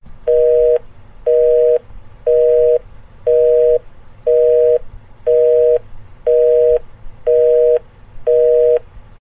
PLAY busy signal sound effect
busy-signal-soundbible.mp3